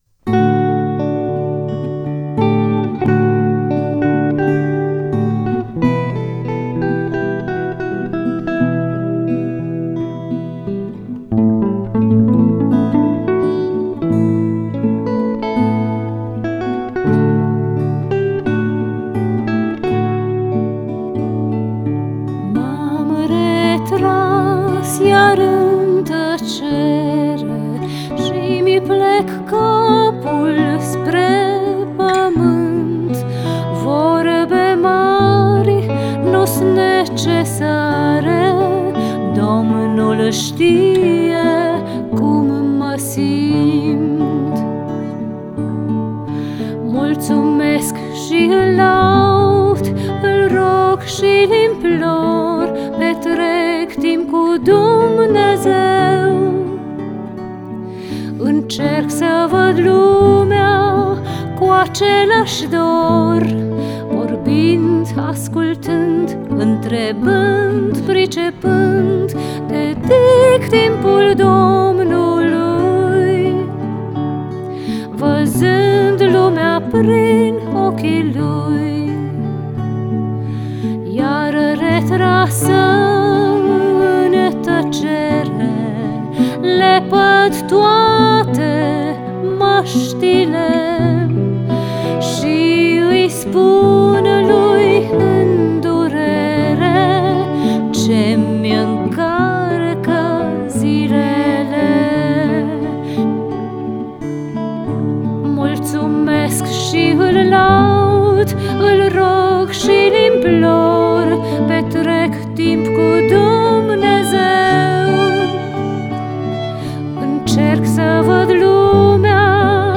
Chant
Guitares et percussions
Violon